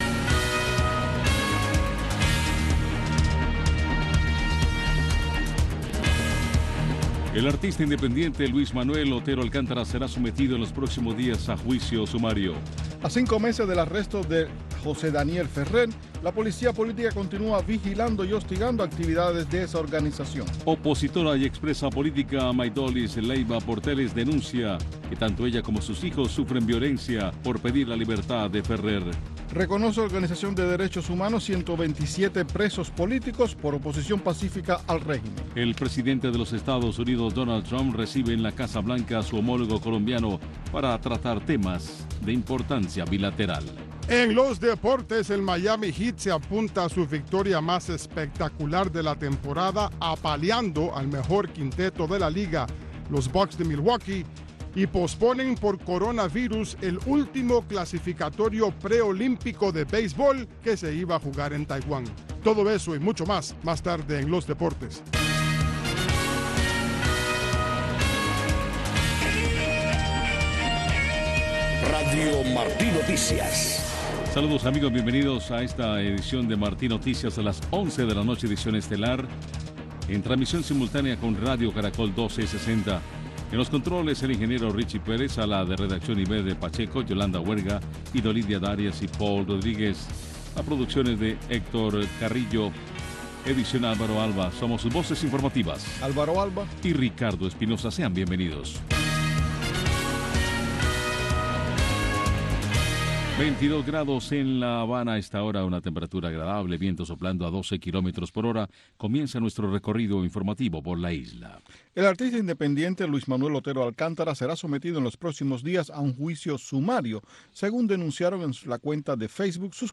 Noticiero de Radio Martí 11:00 PM